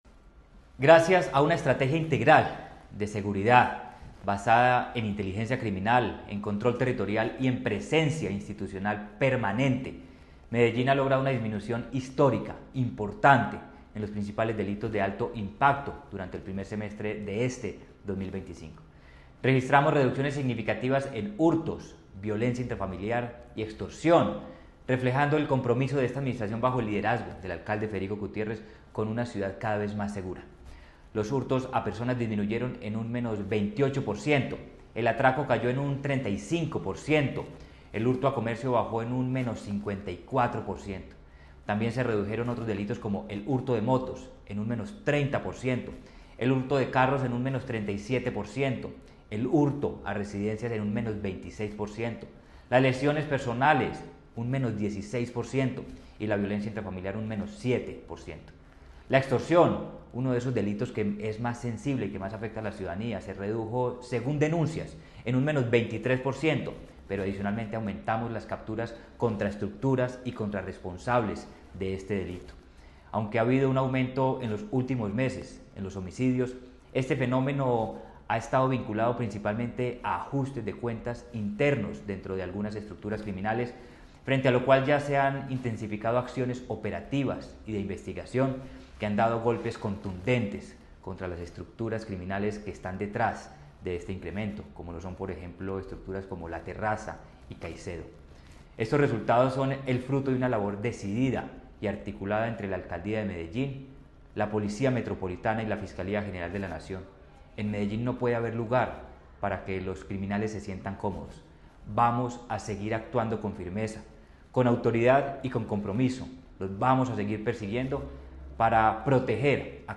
Declaraciones secretario de Seguridad y Convivencia, Manuel Villa Mejía.
Declaraciones-secretario-de-Seguridad-y-Convivencia-Manuel-Villa-Mejia.-Delitos-de-alto-impacto.mp3